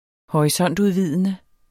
Udtale [ -ˌuðˌviðˀənə ]